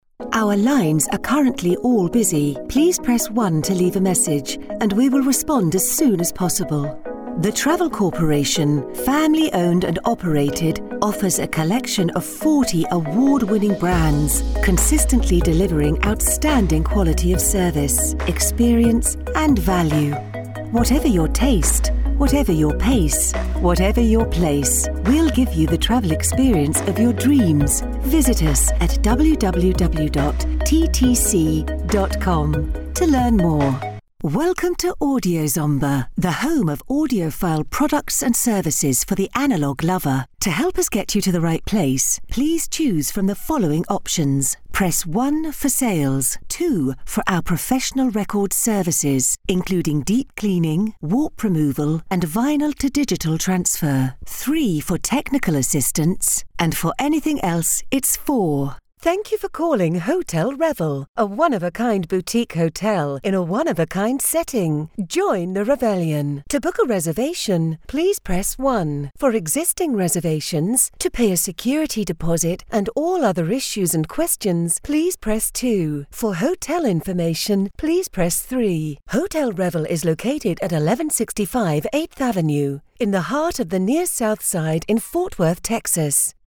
Female
British English (Native)
Assured, Authoritative, Character, Corporate, Engaging, Friendly, Gravitas, Natural, Reassuring, Smooth, Warm, Versatile
Microphone: Neumann TLM 103
Audio equipment: Sound proof booth Scarlett 2i2 interface